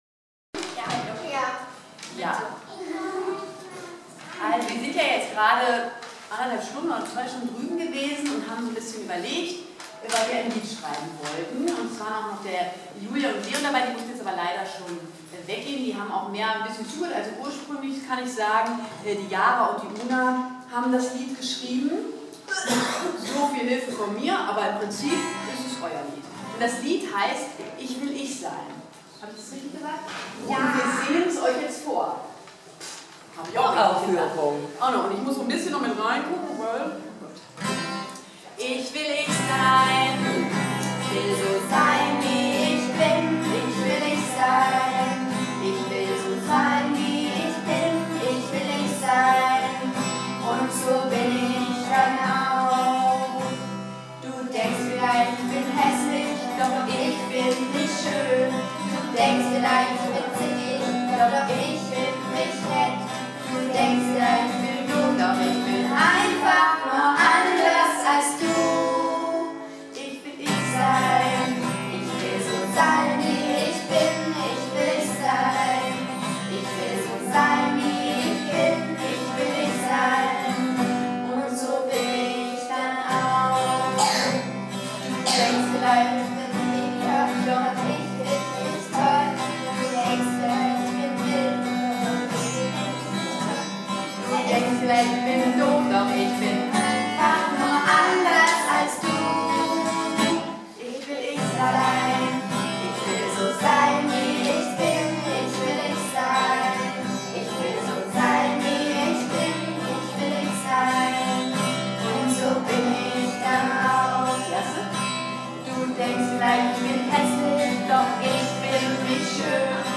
von den Kindern anlässlich des 1. AAK-Kindertags in Herborn am 28.02.2019